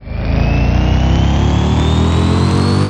apoweron.wav